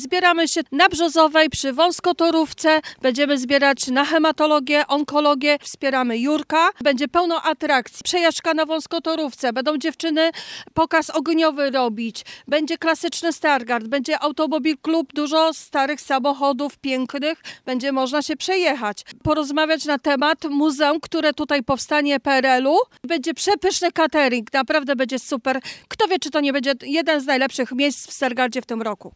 Każdy, kto przyjdzie, znajdzie coś dla siebie — zapowiada Anna Krasoń, radna Rady Miejskiej w Stargardzie.